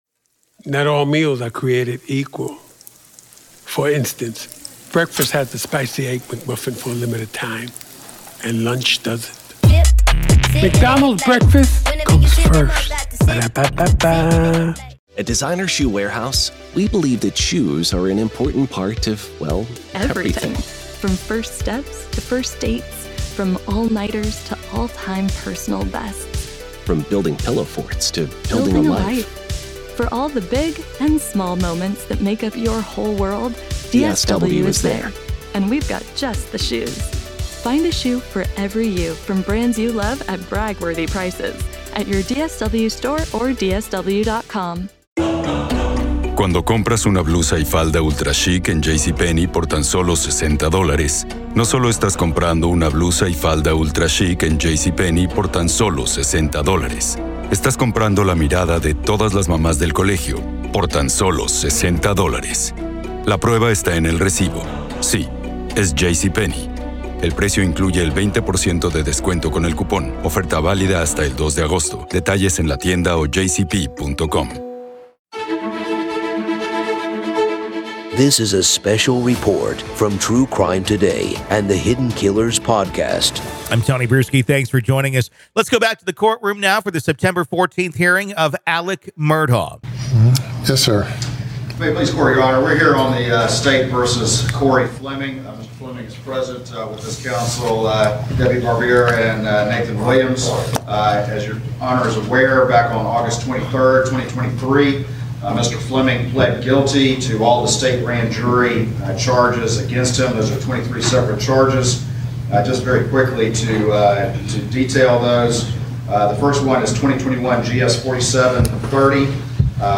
Join us as we delve into raw audio excerpts from the September 14th hearing of Alex Murdaugh.
This episode takes you straight into the heart of the courtroom, offering an unfiltered and immediate perspective of the proceedings. Hear firsthand the prosecution’s arguments, Murdaugh's defense, and the reactions of those present.